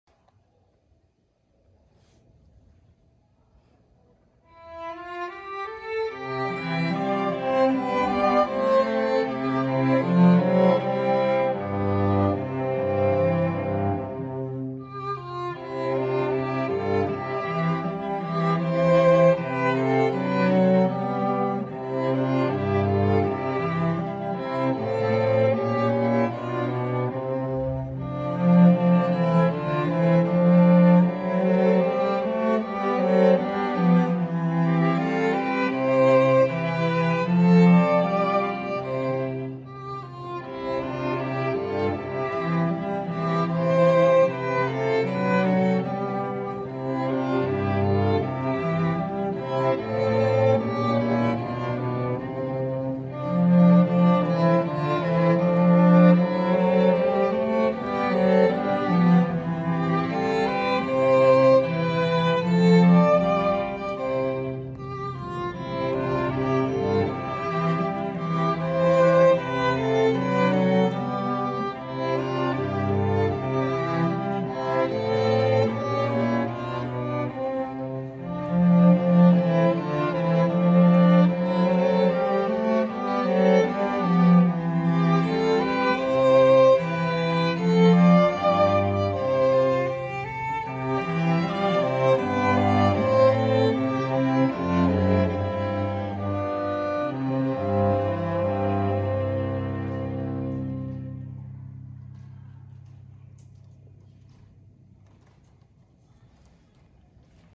校歌（伴奏）
新日本フィル（弦楽四重奏）による校歌演奏